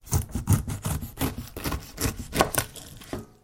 Звук отрезания кусочка ананаса ножом